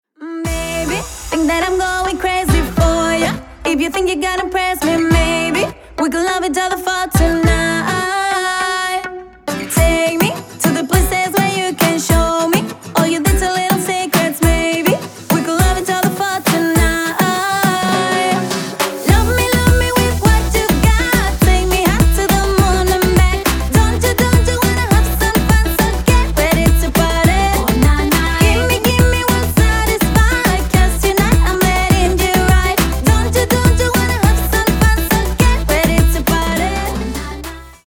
• Качество: 224, Stereo
поп
женский вокал
зажигательные
dance